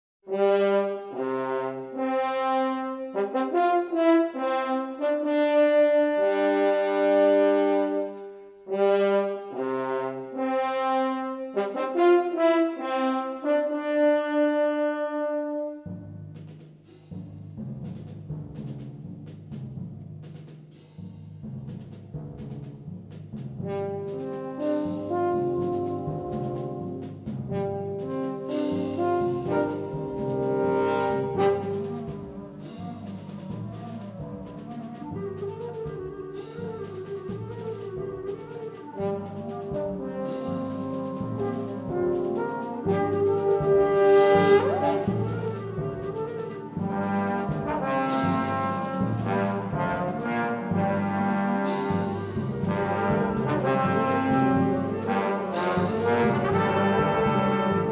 SYMPHONIC BAND
Symphonic poem